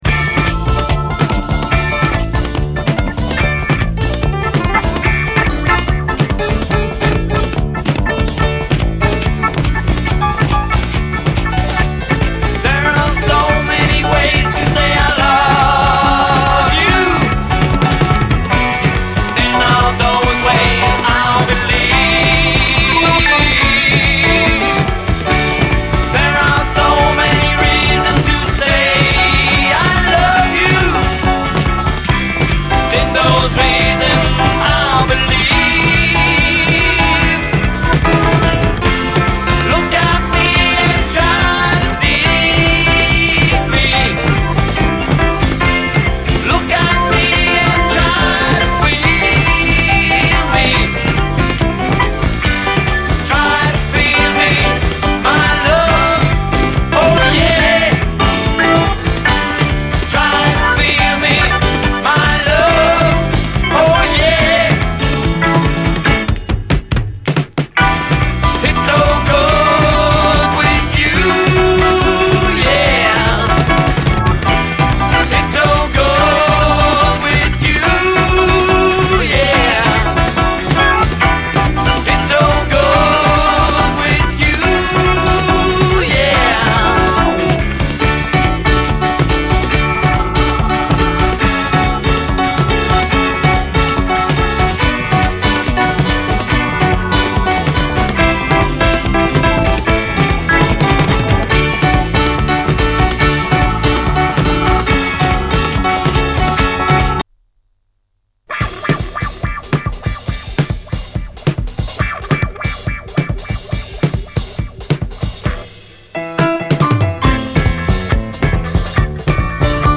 SOUL, 70's～ SOUL, DISCO, 70's ROCK, ROCK, 7INCH
ローカル・フレンチ・カナディアン・バンド！ズンドコ・ディスコ・ビートで駆け抜けるキャッチーな美メロ・ダンサー！
B級ラテン調ラウンジー・ファンク！